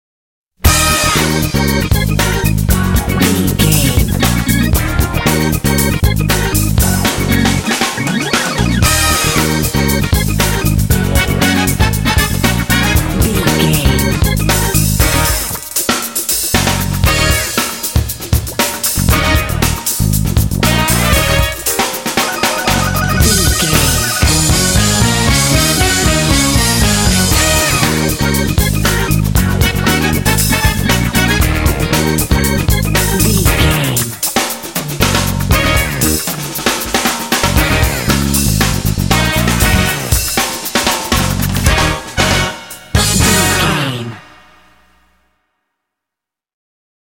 Uplifting
Dorian
E♭
groovy
energetic
driving
electric organ
bass guitar
brass
drums
electric guitar